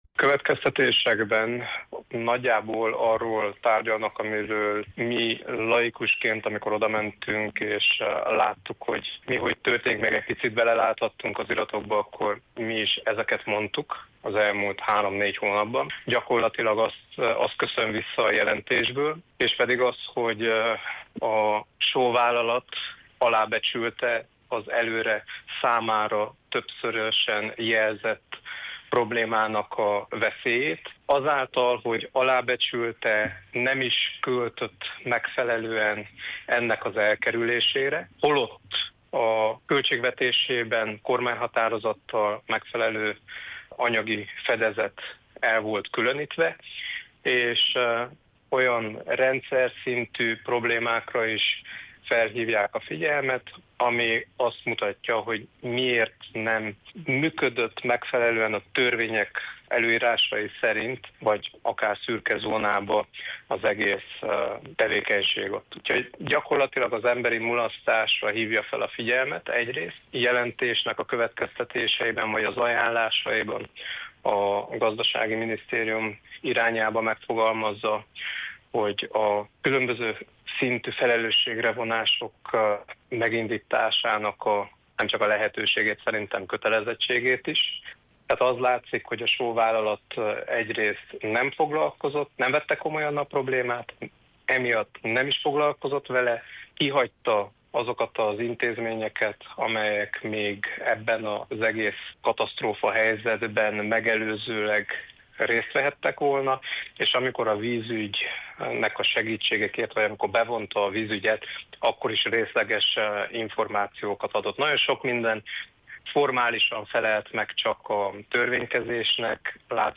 Bíró Barna Botondot, Hargita megye tanácsának elnökét kérdezte